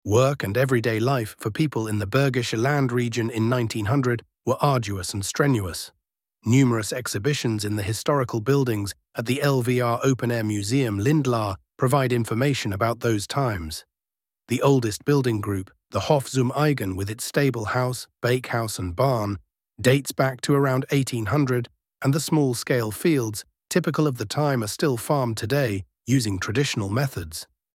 audio-guide-open-air-museum-lindlar-english.mp3